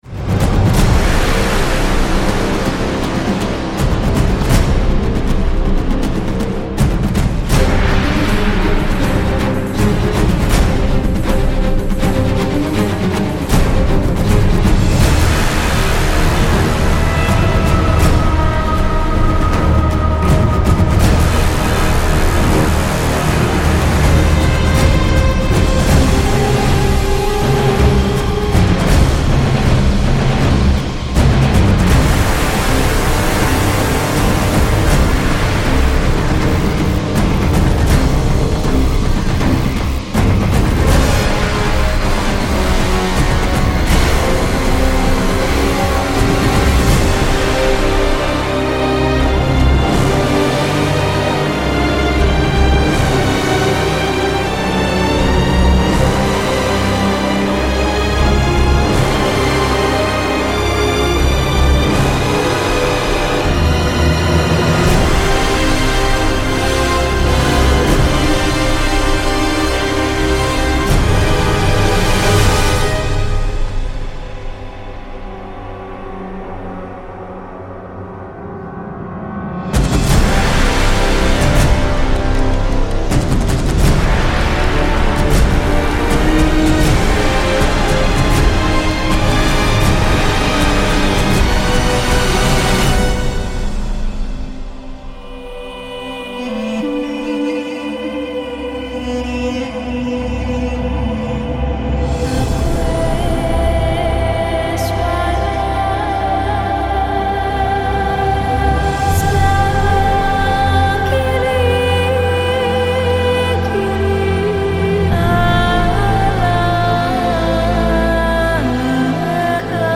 Pauvre thématiquement.